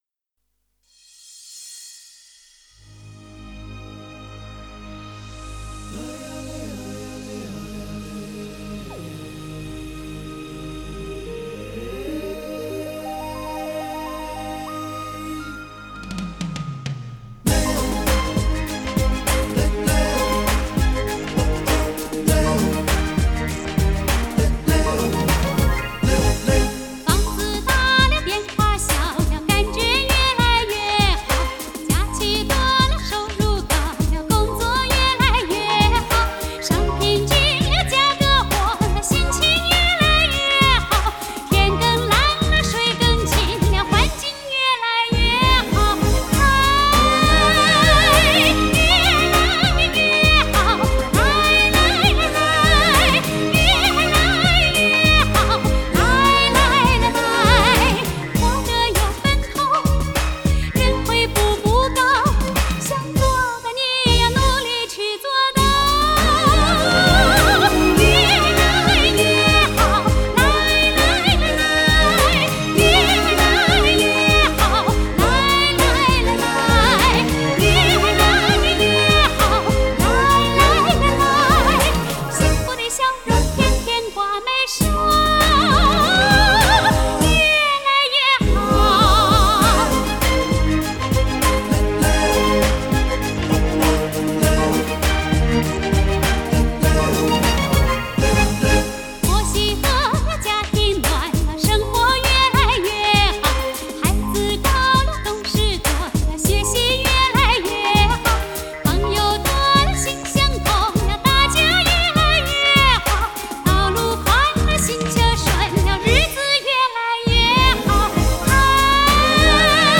Жанр: Chinese pop / Chinese folk